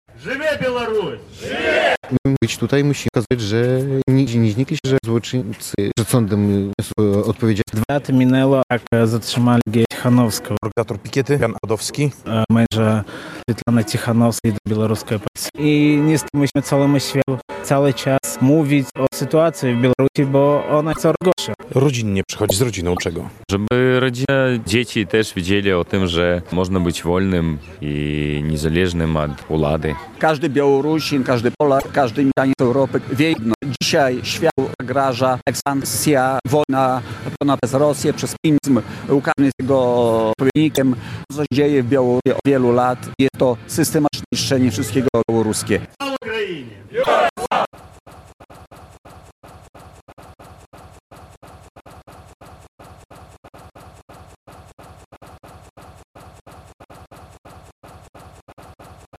Dzień Międzynarodowej Solidarności z Białorusią - pikieta przed białoruskim konsulatem w Białymstoku - relacja